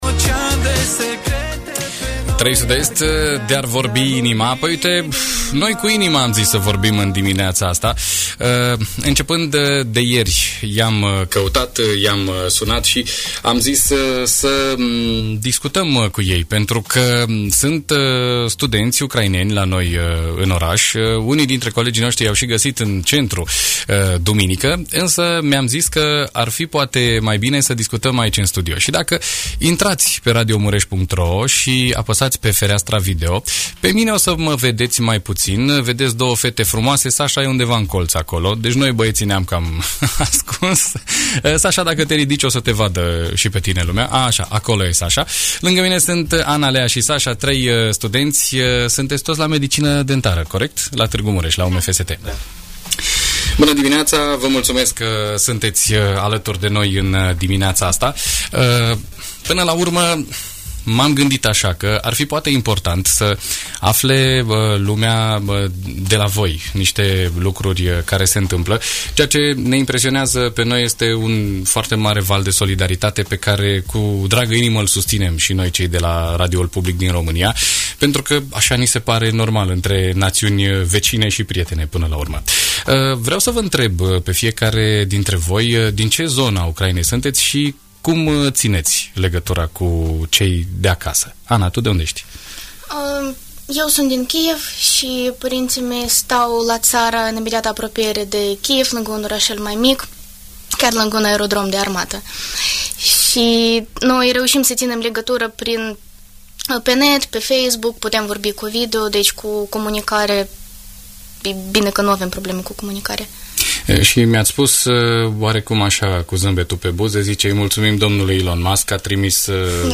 01.-03-BDT-Interviu-Studenti-Ucraineni.mp3